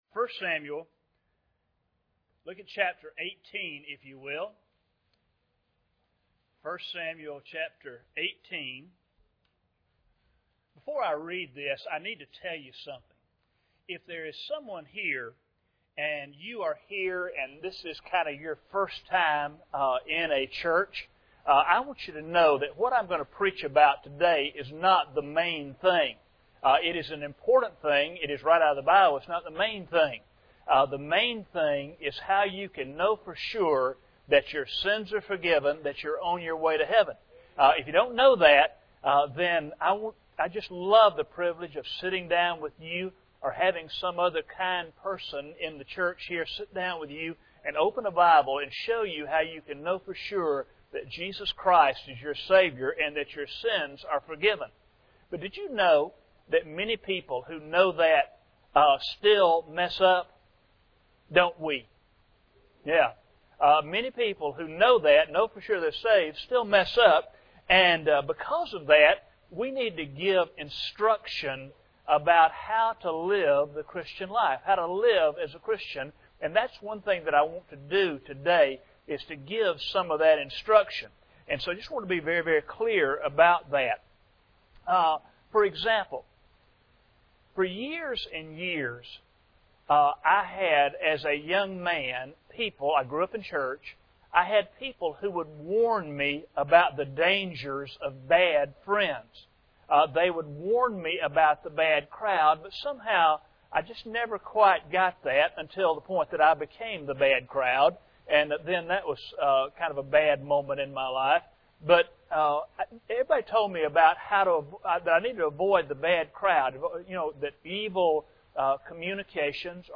Passage: 1 Samuel 18:1 Service Type: Sunday Morning